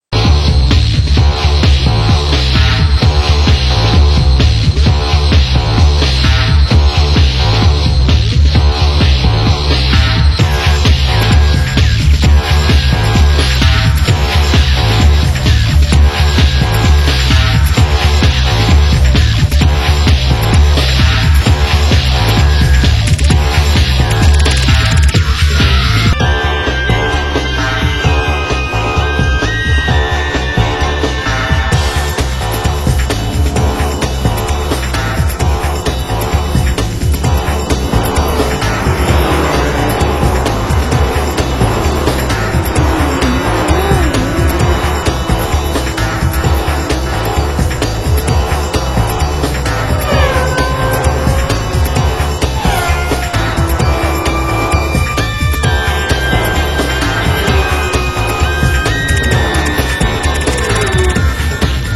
Genre: Leftfield